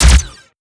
Index of /server/sound/weapons/plasmariflesoundeffects
plasmarifle_firebitch.wav